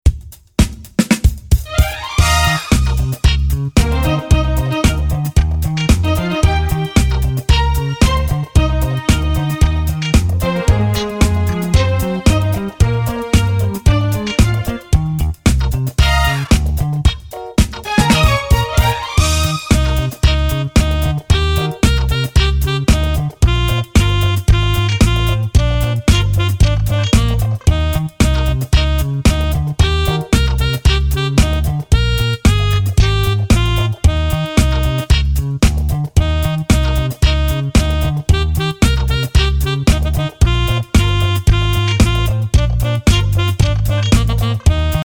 -   Mp3 Mp3 Instrumental Song Track